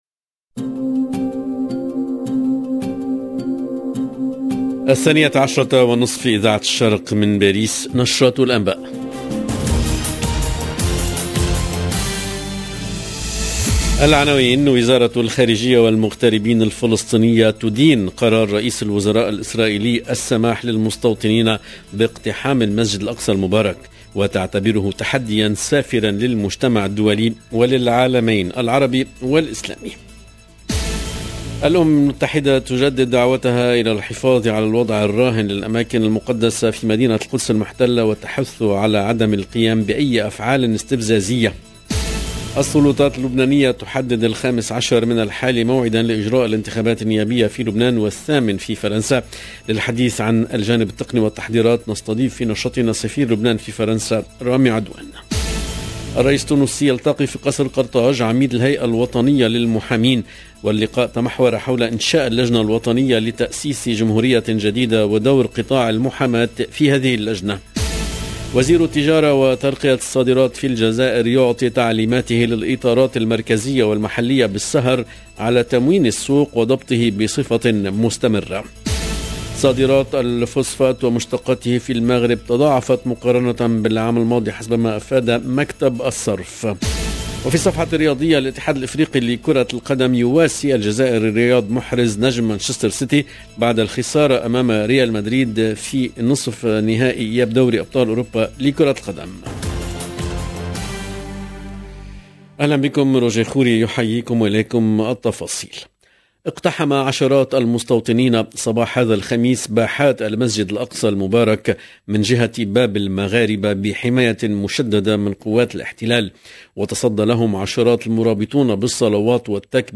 EDITION DU JOURNAL DE 12H30 EN LANGUE ARABE DU 5/5/2022